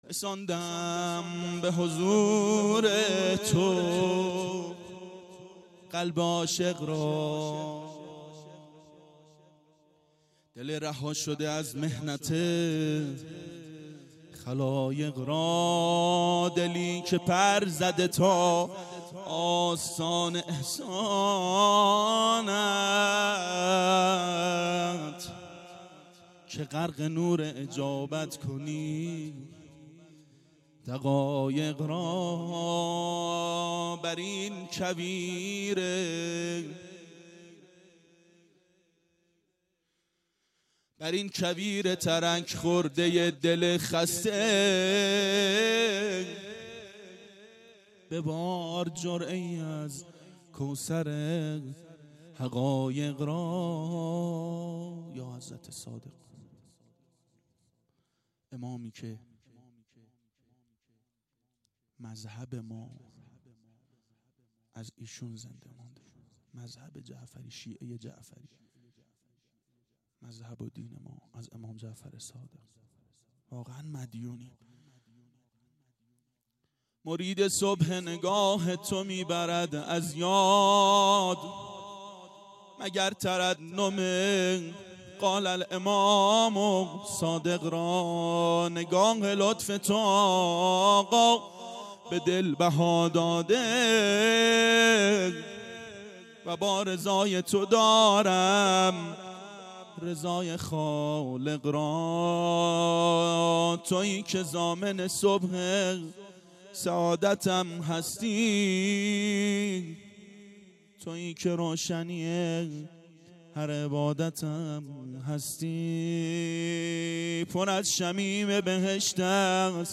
• میلاد پیامبر و امام صادق علیهماالسلام 92 هیأت عاشقان اباالفضل علیه السلام منارجنبان